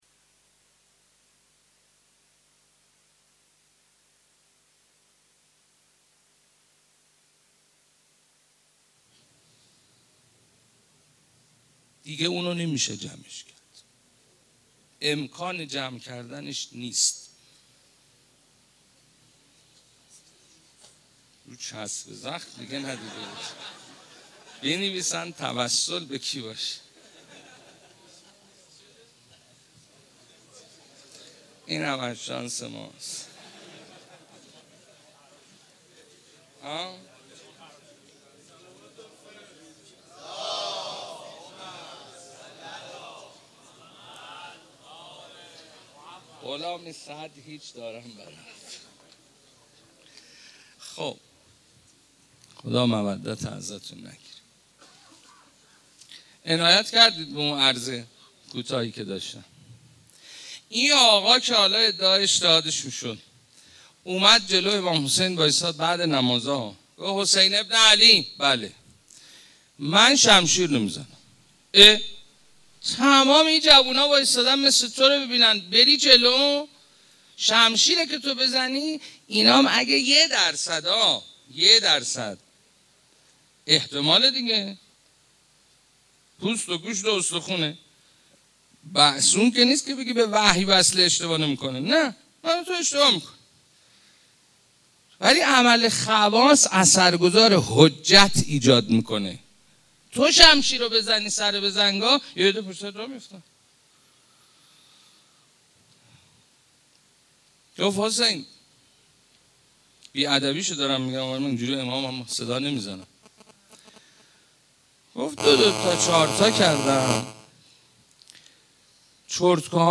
چندرسانه ای Layer ۱ ریشه > ماه رمضان > مناجات > 1393 > شب بیستم ماه رمضان 1435 > سخنرانی > 1- ÈÎÔ Çæá